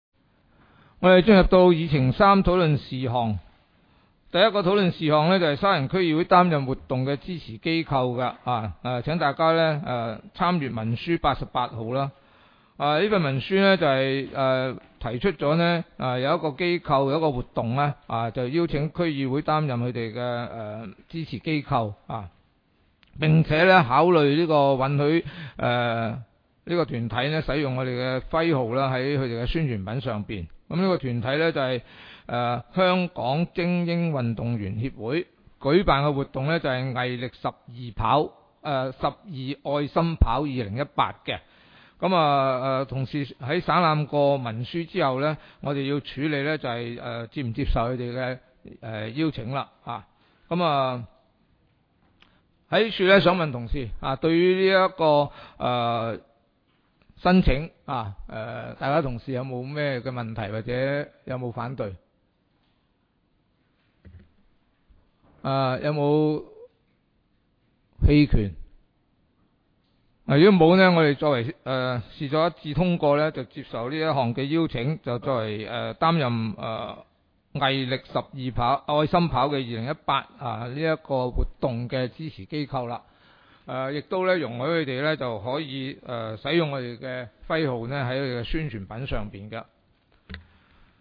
区议会大会的录音记录
地点: 沙田区议会会议室